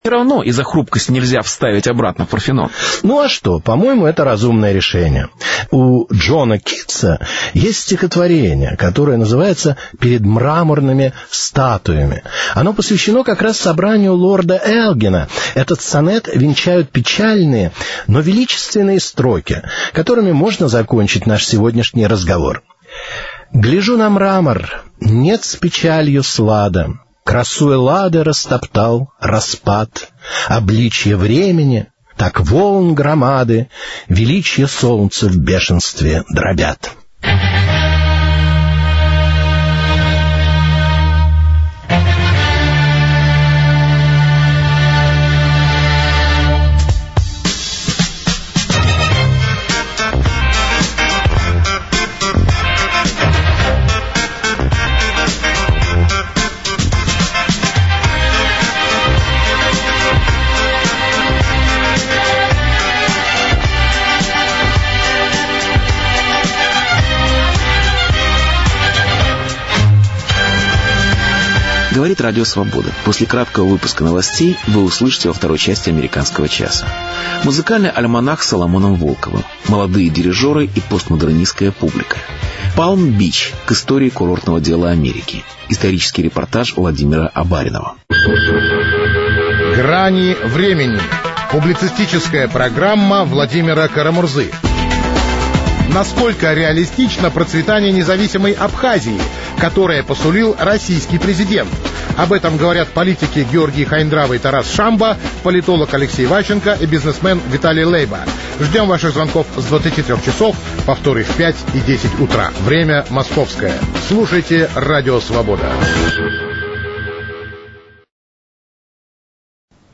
Границы культуры: кому принадлежит Розеттский камень? Беседа с Владимиром Гандельсманом.